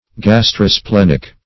Search Result for " gastrosplenic" : The Collaborative International Dictionary of English v.0.48: Gastrosplenic \Gas`tro*splen"ic\, n. [Gastro- + splenic.]